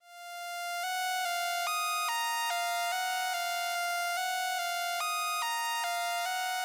Tag: 144 bpm Trap Loops Synth Loops 1.12 MB wav Key : Unknown